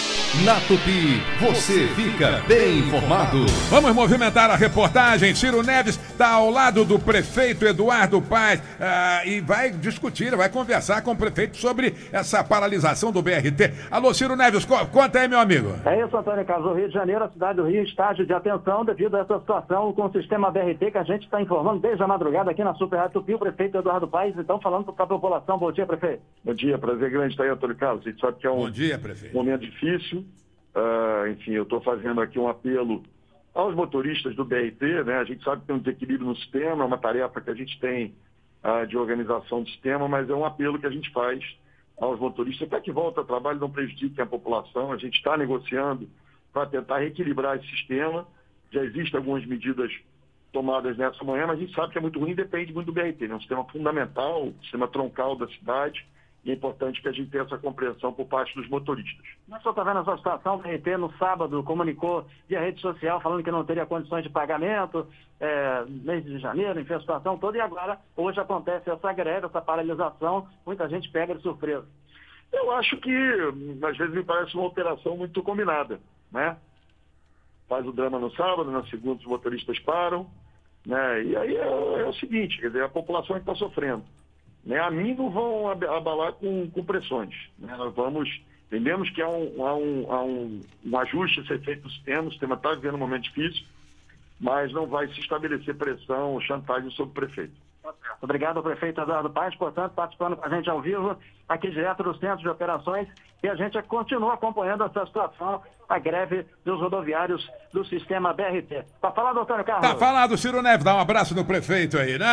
Durante entrevista